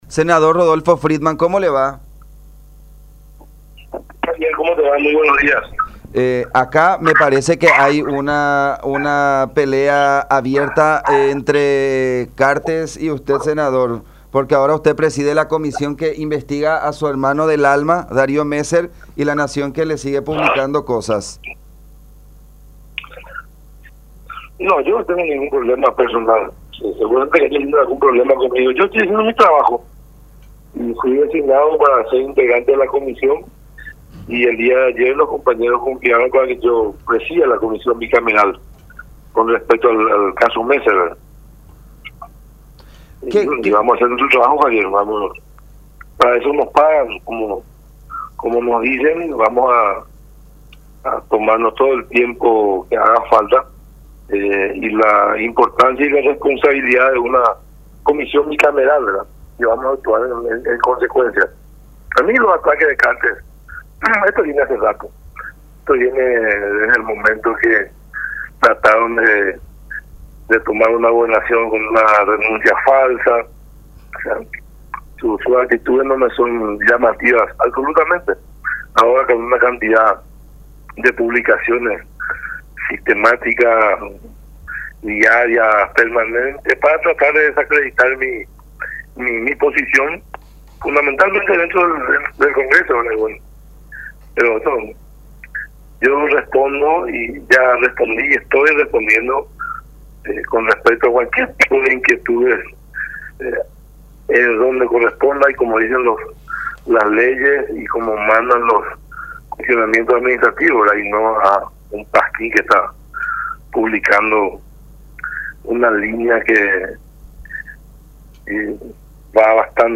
03-SENADOR-RODOLFO-FRIEDMANN.mp3